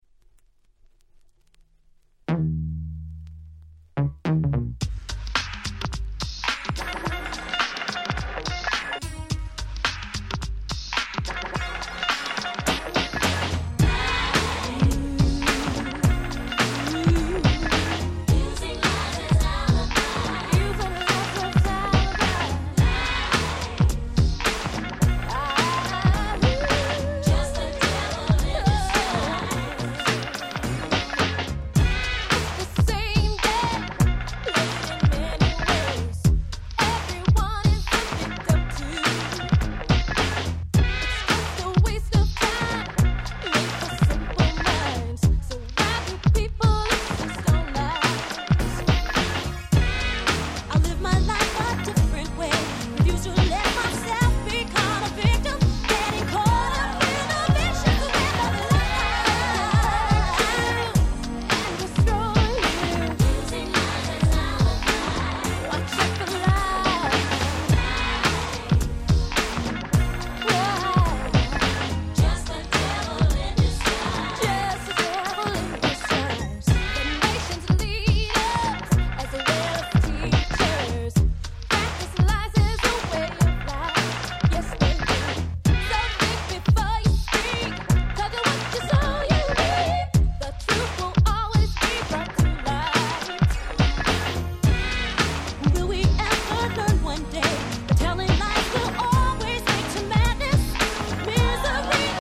ハネたBeatに心弾む最高のGirl's Swingに！！